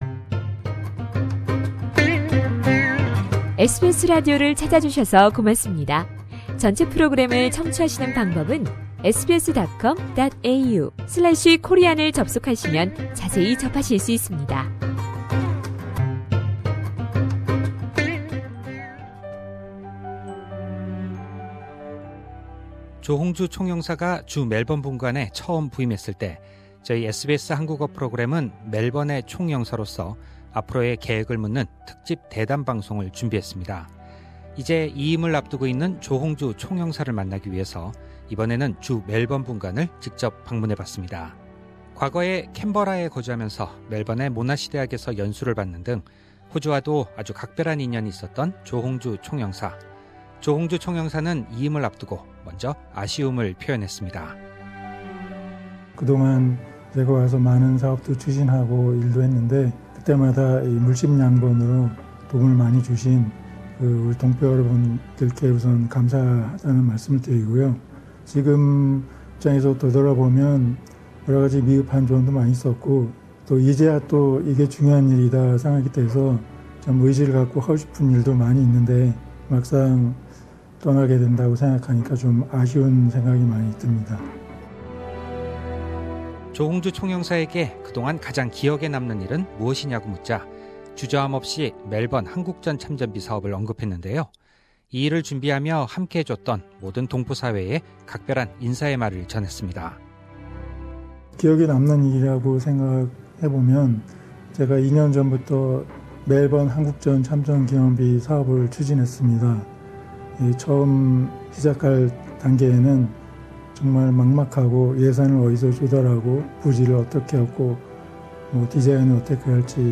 특집 인터뷰: ‘이임 앞둔 주멜번분관 조홍주 총영사’
Hongju Jo, Consul General in Melbourne Source: SBS Korean